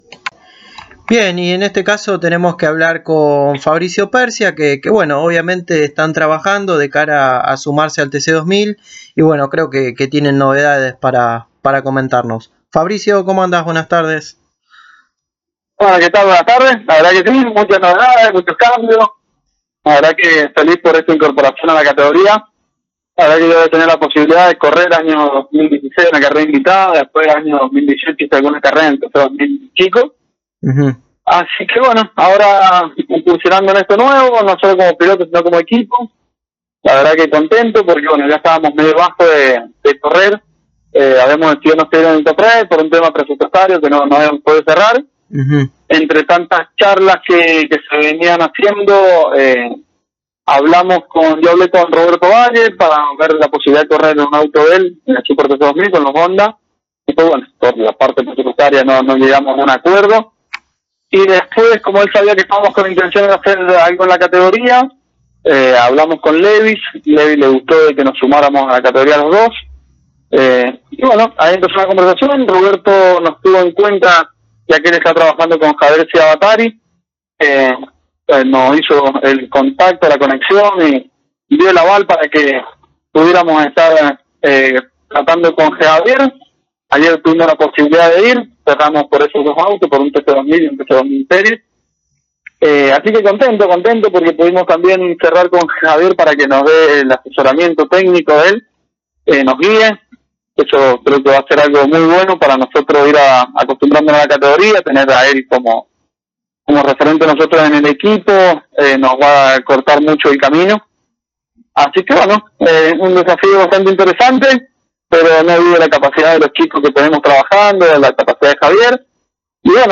El sanjuanino pasó por los micrófonos de Pole Position y confirmó su incorporación junto a su hermano al TC2000 yTC2000 Series